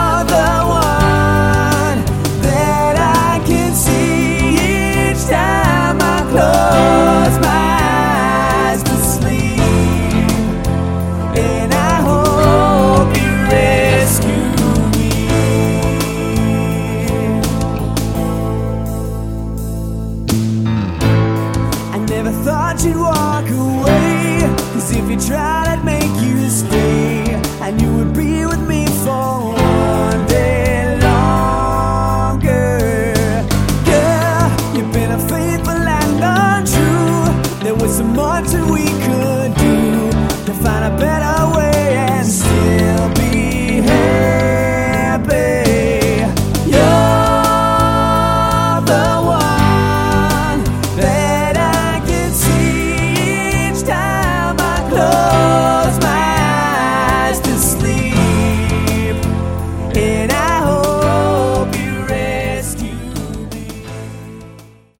Category: AOR
vocals, guitars, keys
bass, vocals
drums, vocals